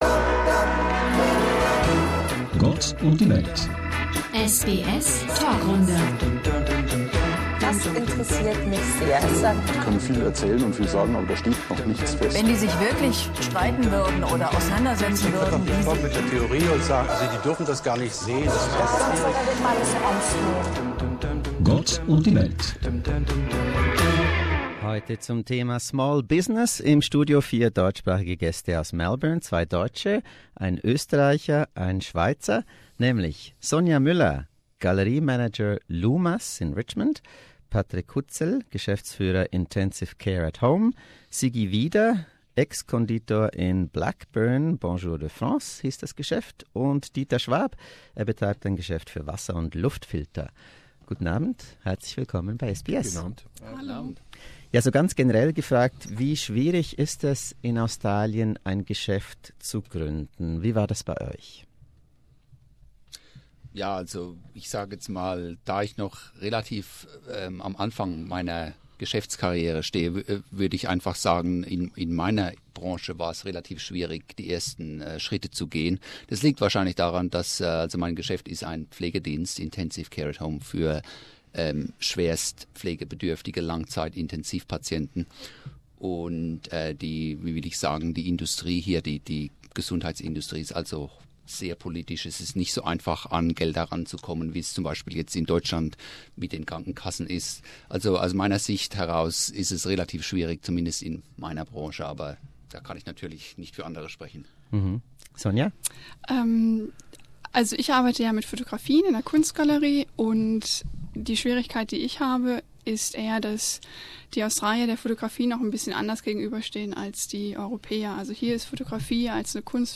To mark the launch, SBS German aired a panel discussion with four German speaking guests!